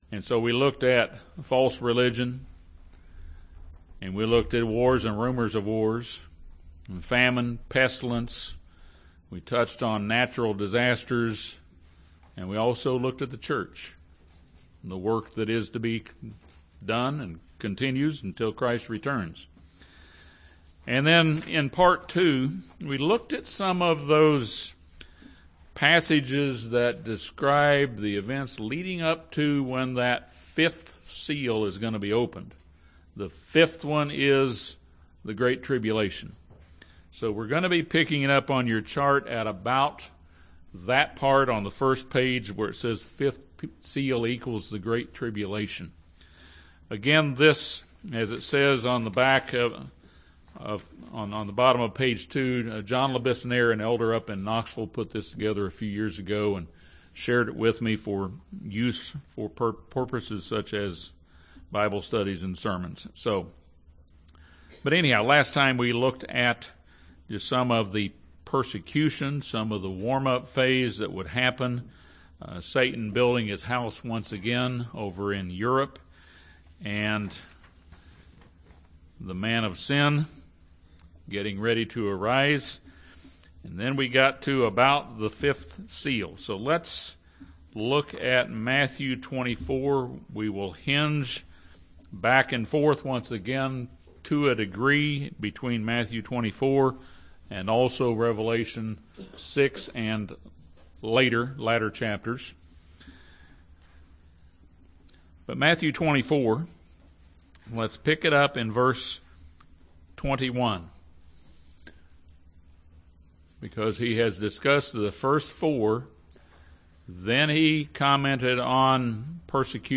Part 3 of our overview of prophecy discusses the events that will occur during the Great Tribulation which is the time of Satan's wrath upon mankind. The sermon also discusses the 6th and 7th seals of Revelation which are the heavenly signs and the Day of the Lord.